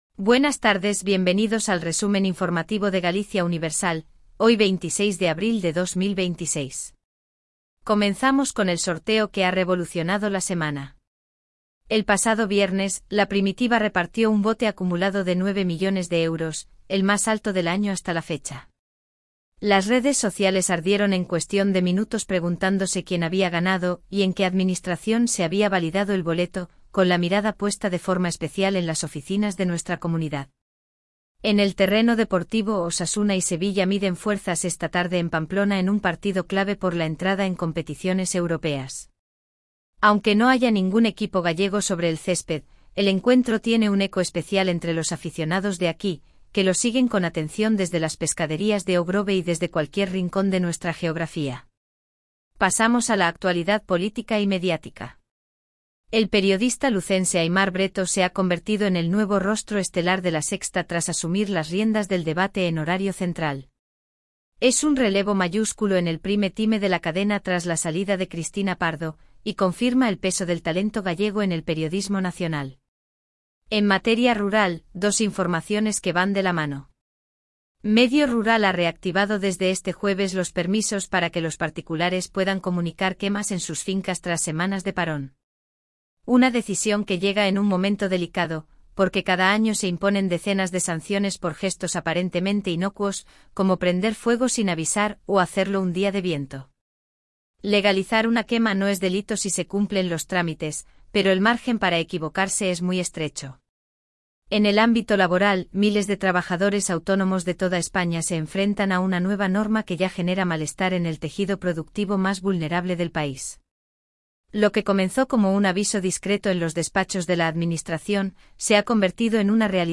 Resumen informativo de Galicia Universal
🎤 Locutora